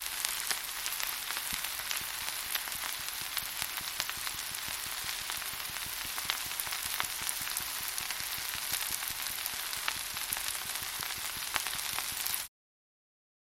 FoodVeggies, Fry
Sizzling sound effects like this are great to showcase the cooking process. It’s the right volume and tone to convey an assured hand on the stove.